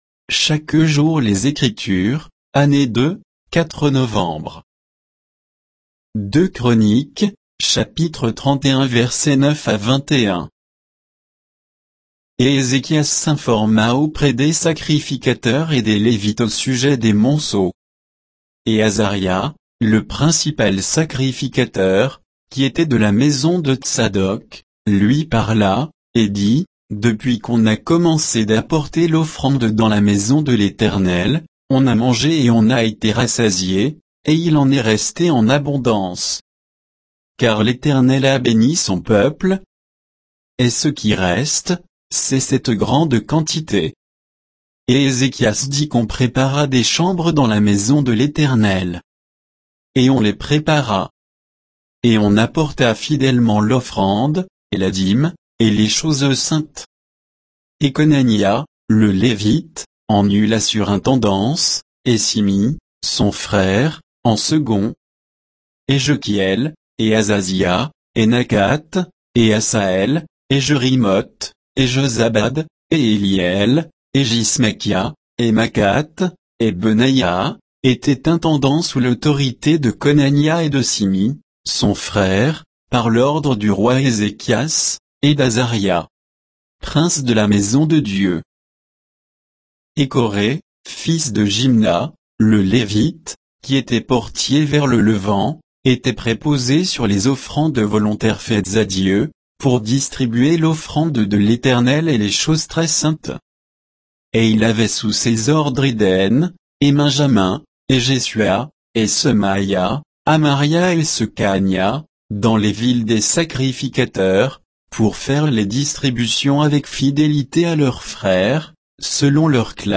Méditation quoditienne de Chaque jour les Écritures sur 2 Chroniques 31, 9 à 21